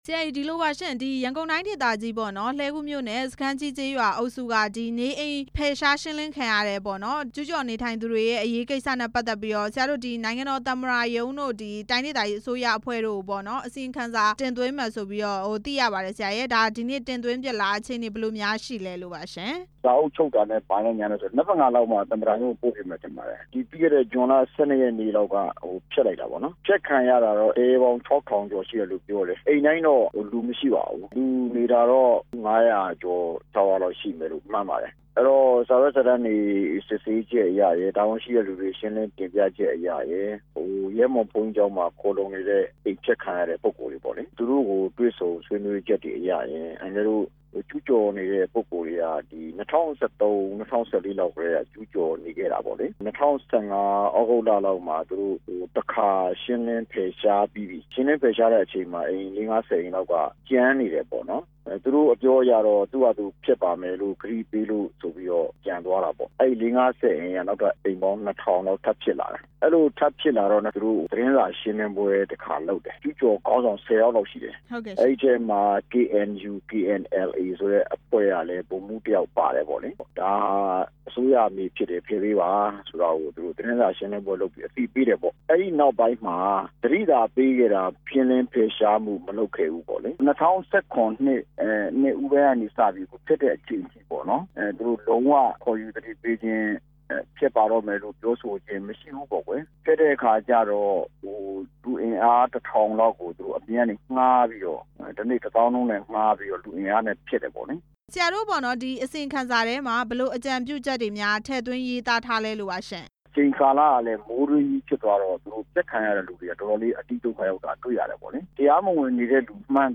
လှည်းကူး ကျူ းကျော်ကိစ္စ မေးမြန်းချက်